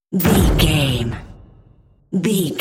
Dramatic hit slam door rvrb
Sound Effects
Atonal
heavy
intense
dark
aggressive